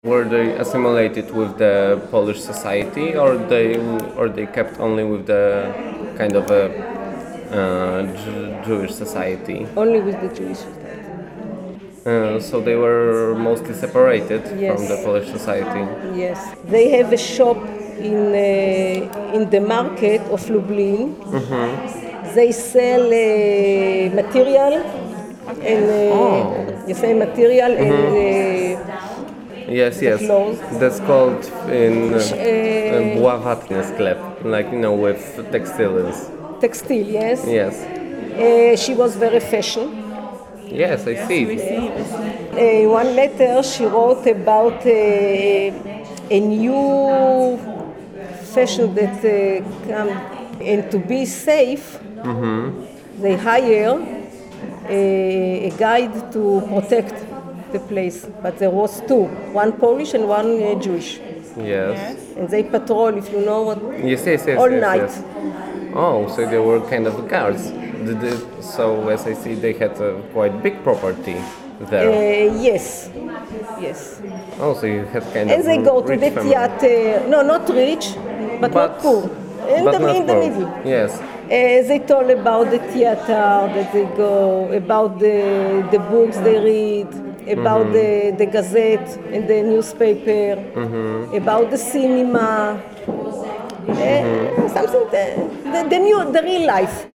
Wywiad przeprowadzony w ramach wydarzenia artystycznego: Misterium Pamięci ,,Ocalone Losy'', które miało miejsce 17 marca 2012 r. w Ośrodku „Brama Grodzka – Teatr NN” z okazji 70. rocznicy likwidacji lubelskiego getta na Podzamczu. W ramach Misterium Ocaleni z Zagłady lubelscy Żydzi oraz ich potomkowie opowiadali uczniom lubelskich szkół historie swoich rodzin.